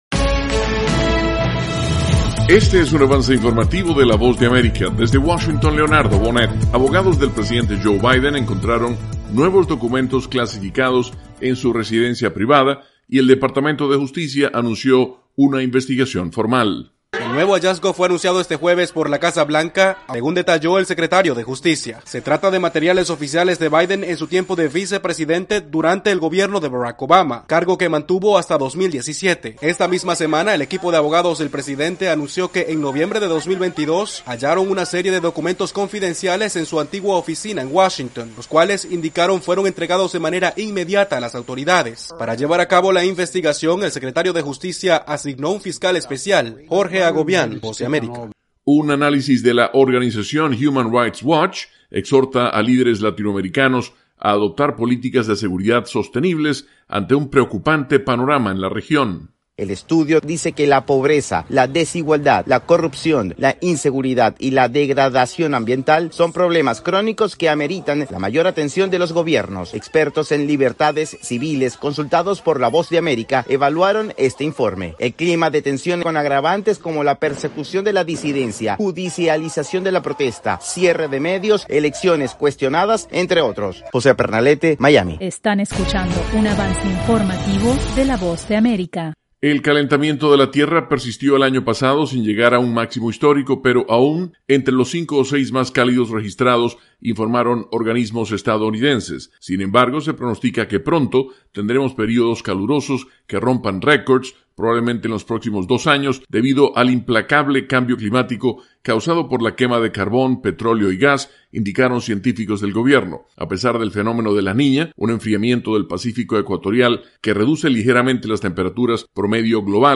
Avance Informativo 7:00 PM